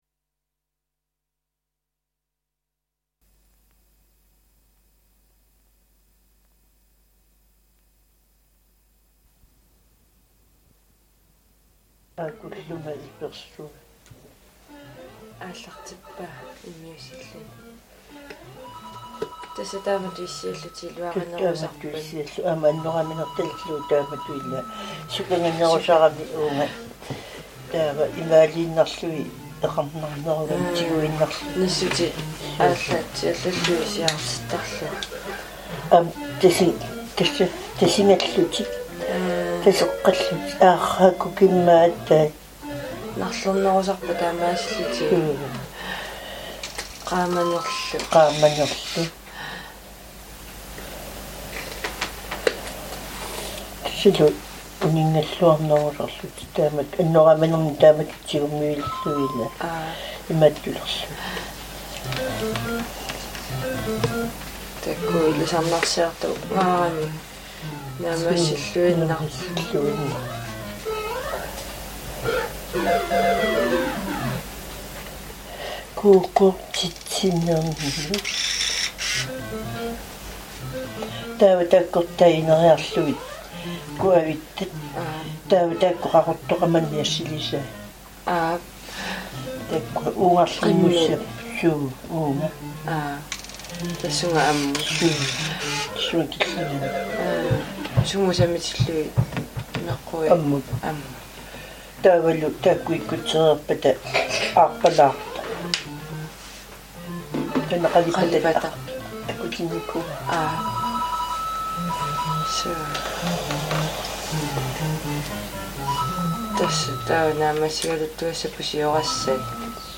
in conversation
From the sound collections of the Pitt Rivers Museum, University of Oxford, being one of a number of miscellaneous individual recordings.